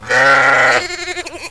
SHEEP1.WAV